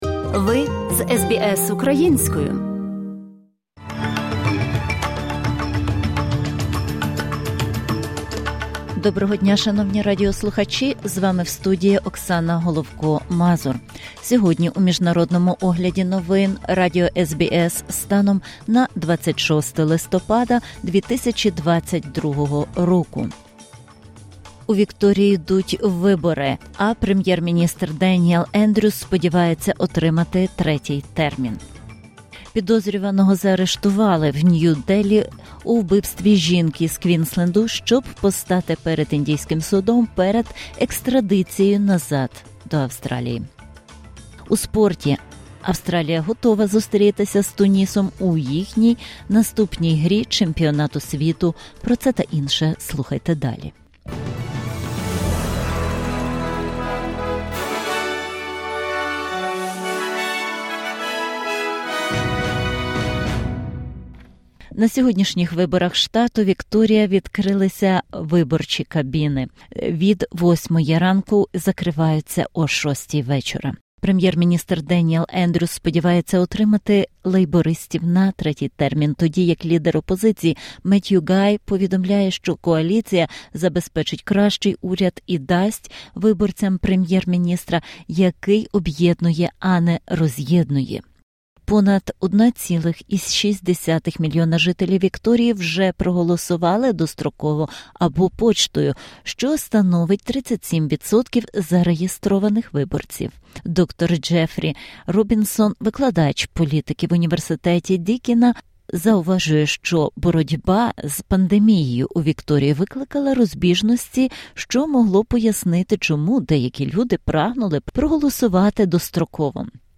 SBS news in Ukrainian -26/11/2022